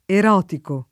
[ er 0 tiko ]